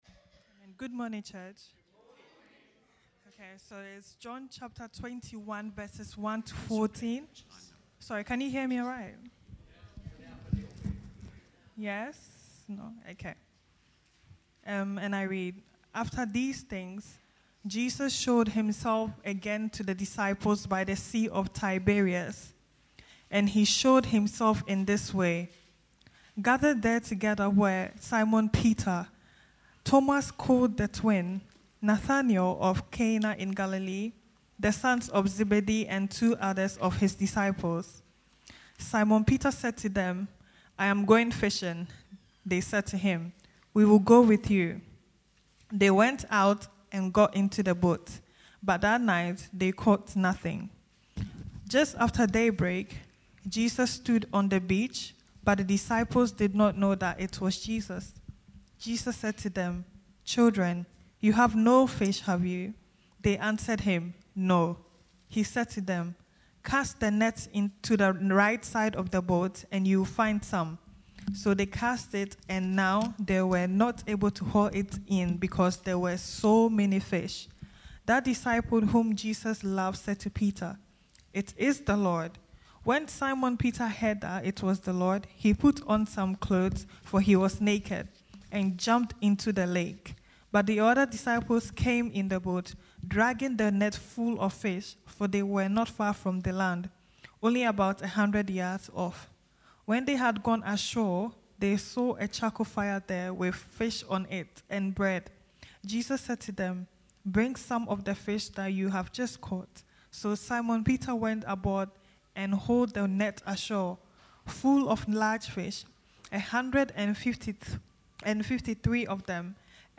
A Sunday sermon